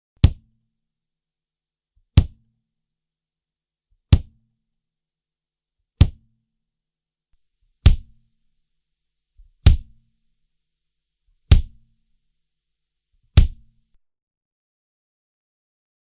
la sonorité est bien chaleureuse et les distorsion (si on cherche à en obtenir par l'émulation de lampe ou par le compresseur) restent très musicales avec des harmoniques qui n'agressent pas
voici quelques exemple (fait en deux minutes avec les presets) vous avez toujours au départ le son d'origine et en deuxième partie le son traité avec le channel-strip Nomad
le kick (réalisé seulement avec le preset de base (il y en a 3 pour le kick)
792kick.mp3